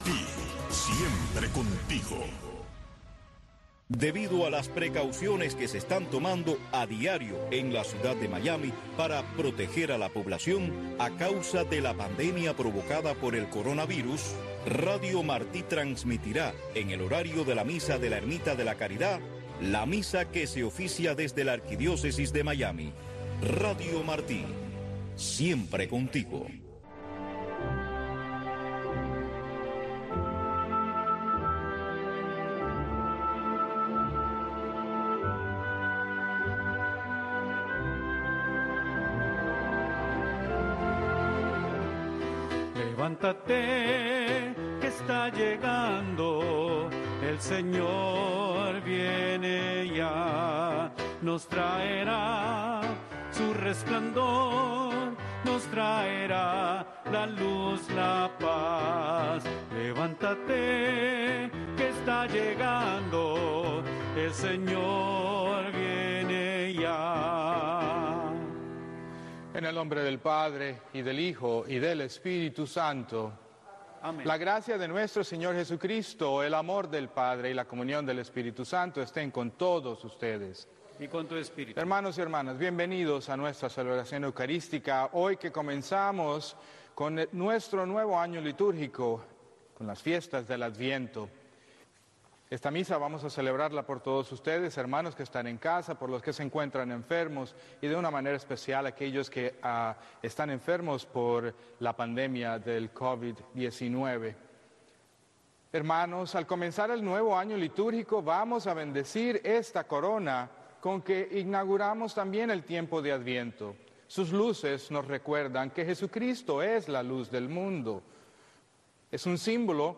La Santa Misa
El Santuario Nacional de Nuestra Señor de la Caridad, más conocido como la Ermita de la Caridad, es un templo católico de la Arquidiócesis de Miami dedicado a Nuestra Señora de la Caridad, Patrona de Cuba.